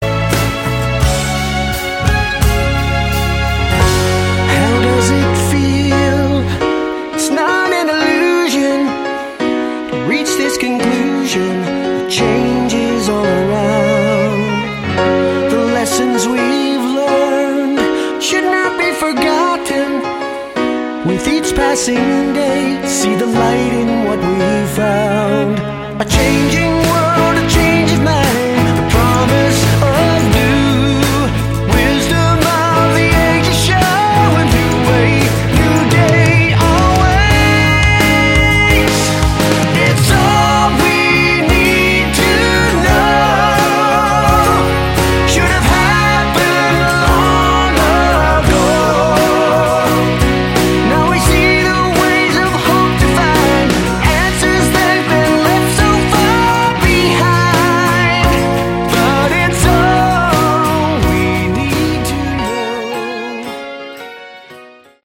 Category: AOR / Prog
guitars, bass, keyboards
vocals
drums